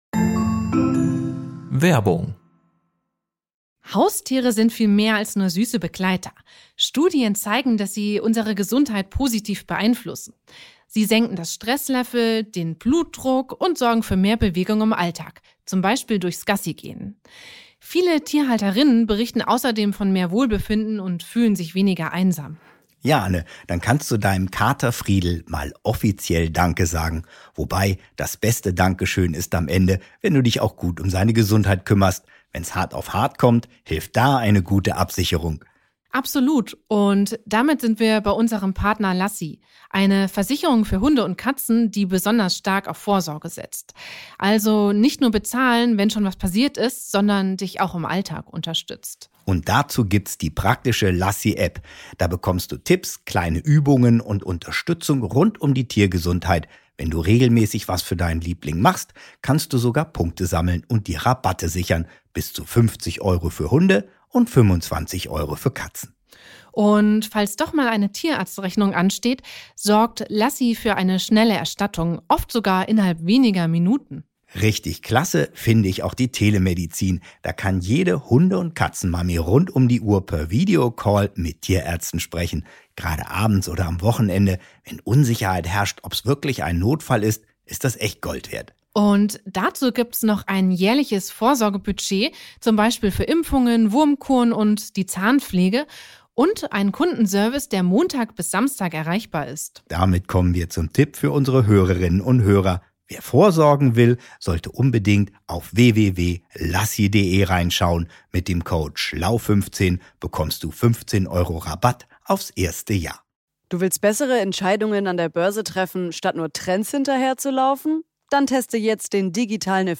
diskutiert mit dem Lesepädagogen und Kinderbuchautor